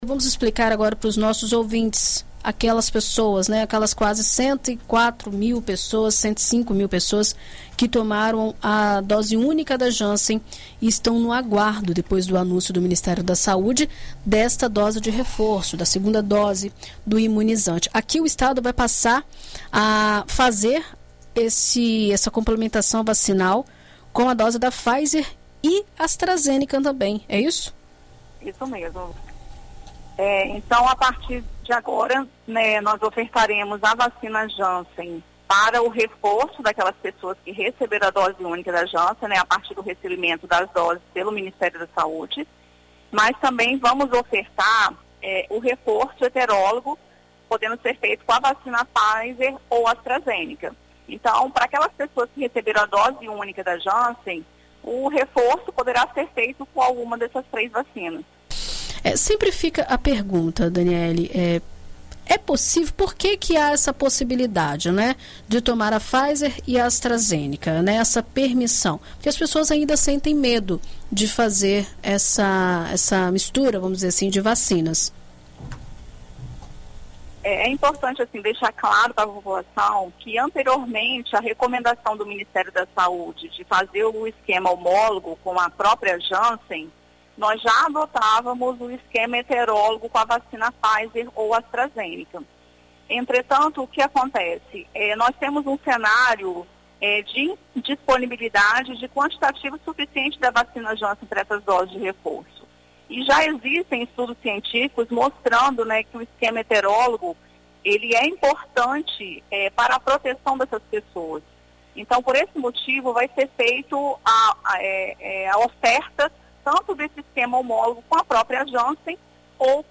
Em entrevista à BandNews FM Espírito Santo nesta quinta-feira (09)